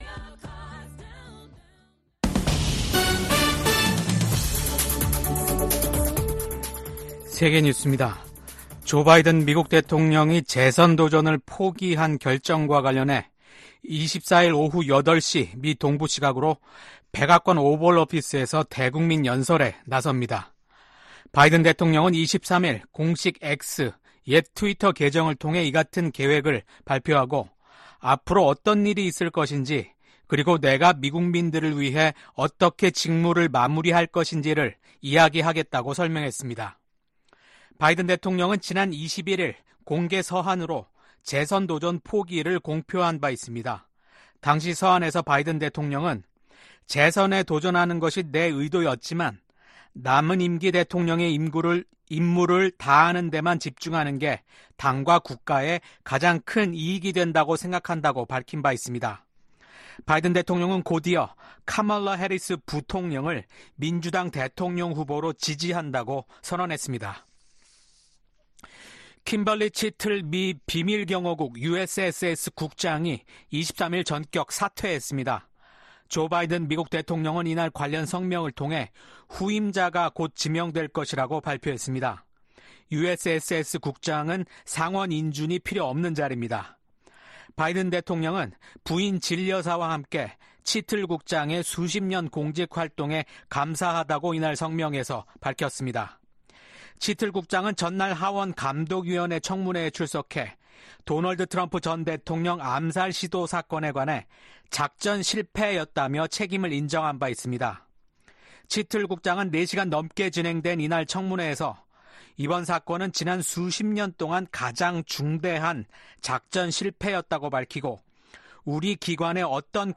VOA 한국어 아침 뉴스 프로그램 '워싱턴 뉴스 광장' 2024년 7월 24일 방송입니다. 도널드 트럼프 전 대통령이 공화당 대선 후보 수락 연설에서 미국 사회의 불화와 분열이 빠르게 치유돼야 한다고 강조했습니다. 미한일 합참의장이 3국 다영역 훈련인 프리덤 에지 훈련을 확대하기로 합의했습니다. 북한 군인들이 국제법에 반하는 심각한 강제노동에 시달리고 있다는 우려가 국제사회에서 제기되고 있습니다.